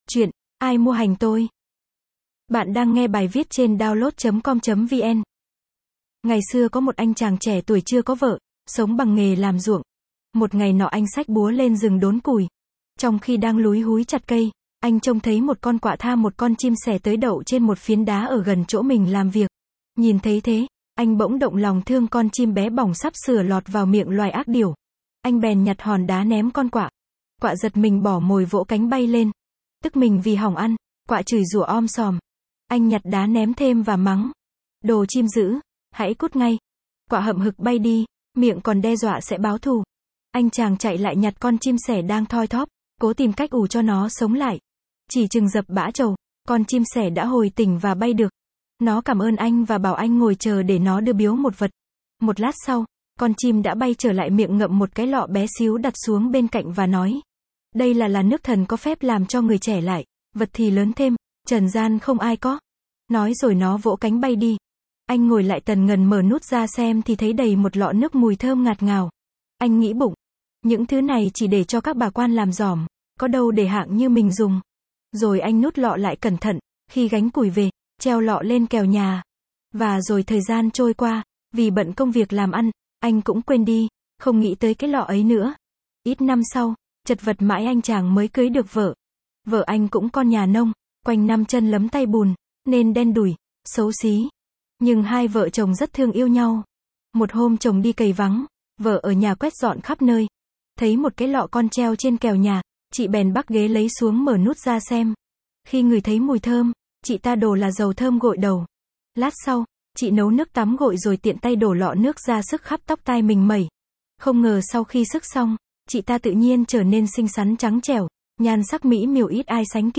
Sách nói | Ai mua hành tôi